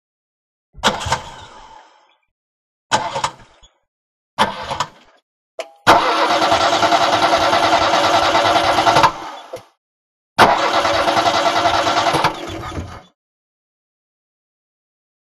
Automobile; False Start; Audi 80l Trying To Start.